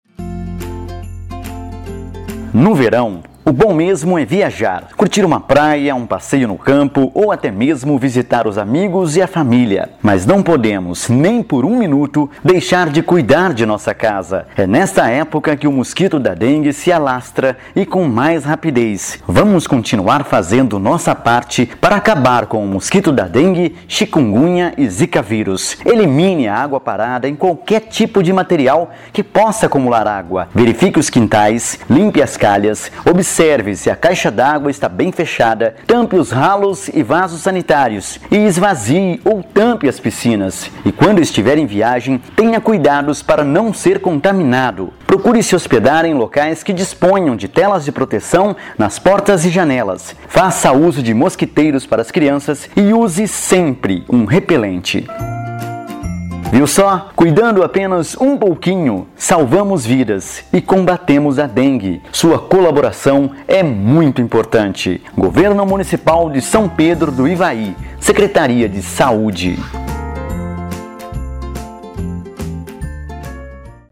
Spot de Rádio sobre os cuidados com a casa durante período de férias